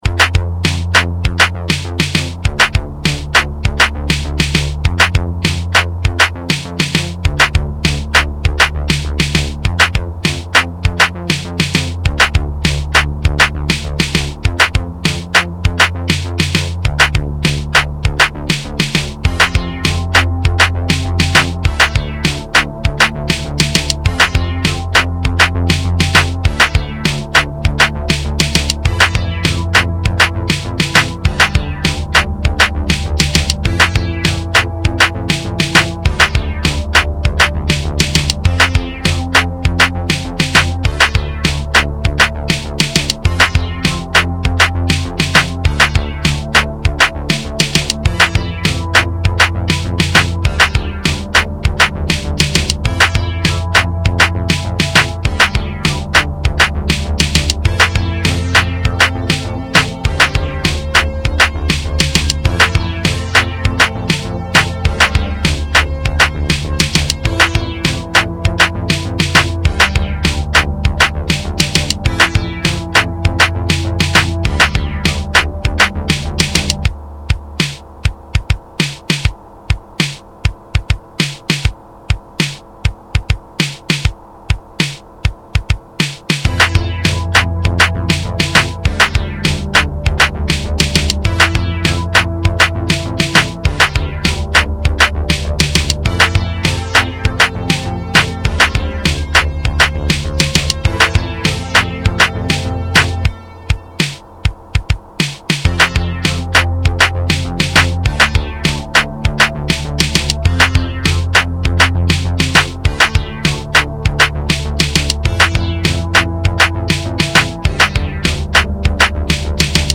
dance/electronic
IDM
Eighties/synthpop